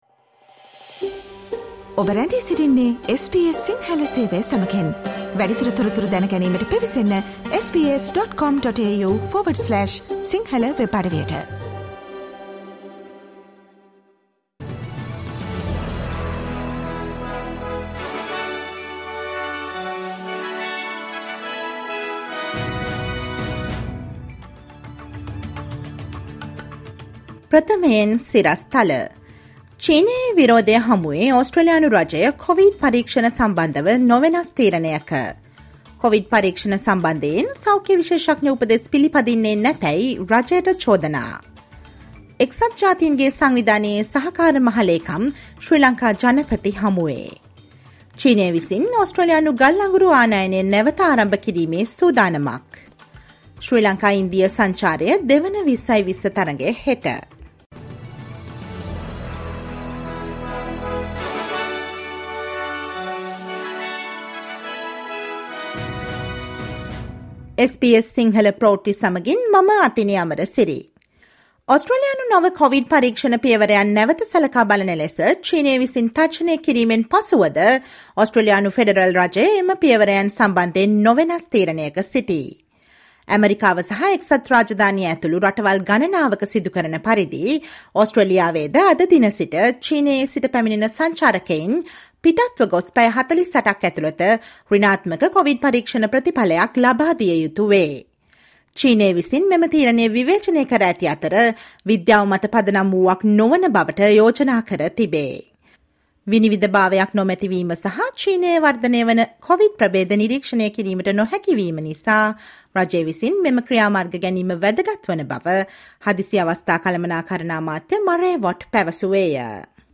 Listen to the latest news from Australia, Sri Lanka, and across the globe, and the latest news from the sports world on SBS Sinhala radio news – Thursday, 05 January 2023.